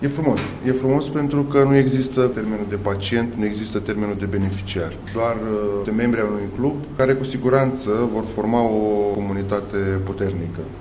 Marius Lacătuş, directorul general al Direcţiei Generale de Asistenţă Socială şi Protectia Copilului Sector 6 s-a declarat încântat de acest loc: